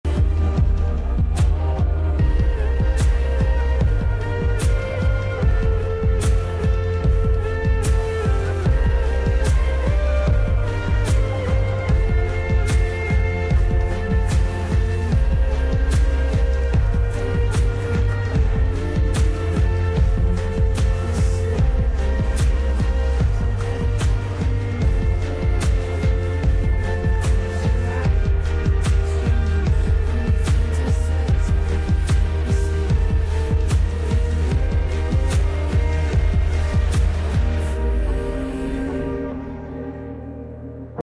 backing vocals slowed version